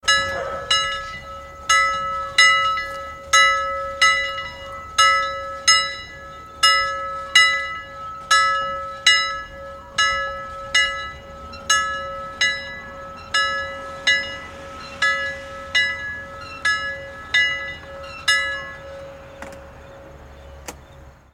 جلوه های صوتی
دانلود صدای زنگوله 3 از ساعد نیوز با لینک مستقیم و کیفیت بالا